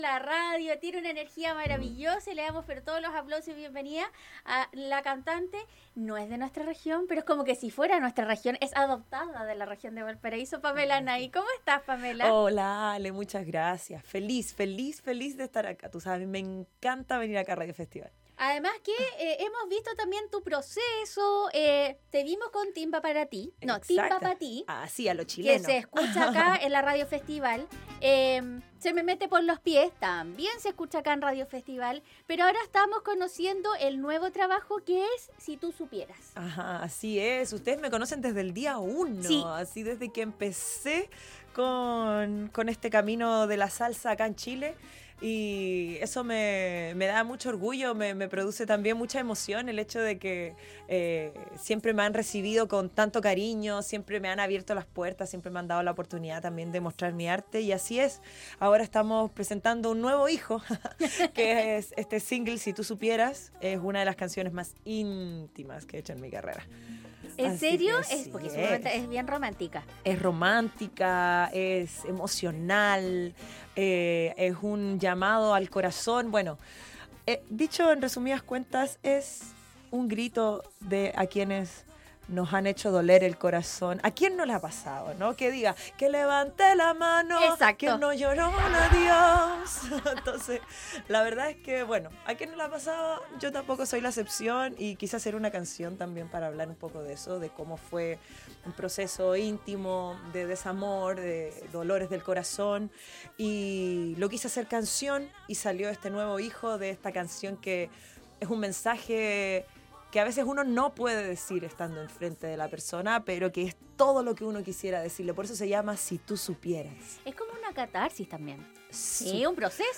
visitó los estudios en Colores